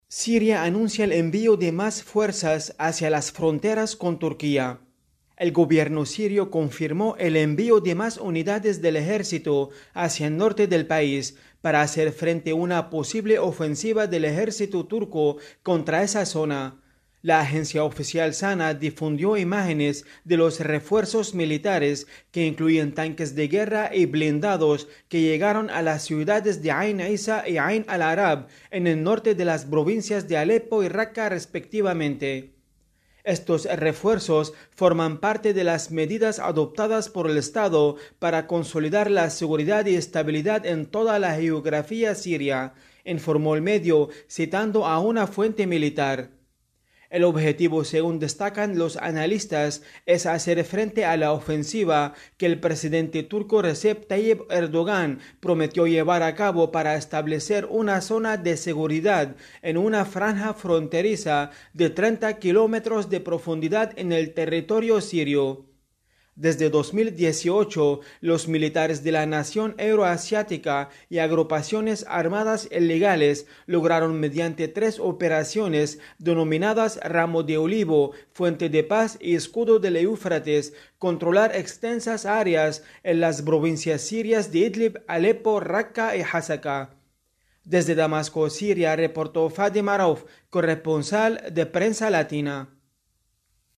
desde Damasco